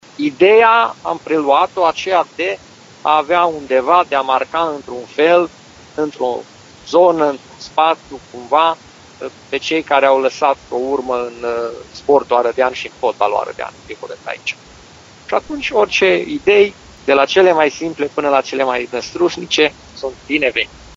Autoritățile din Arad așteaptă propuneri pentru cele mai bune variante, a anunțat, astăzi, primarul Călin Bibarț: